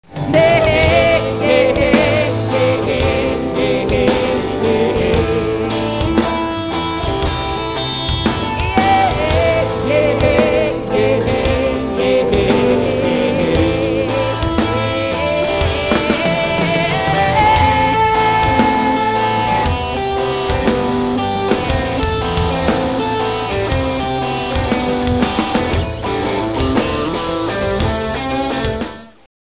Starting off with slow, long howls